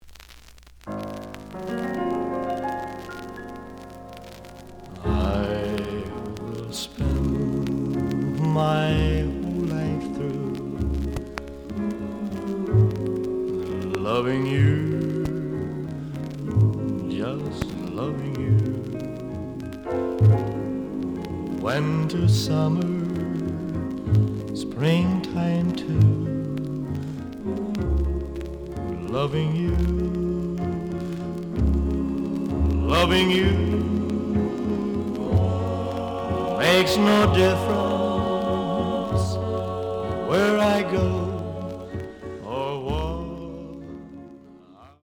The audio sample is recorded from the actual item.
●Genre: Rhythm And Blues / Rock 'n' Roll
Some noise on first half of B side.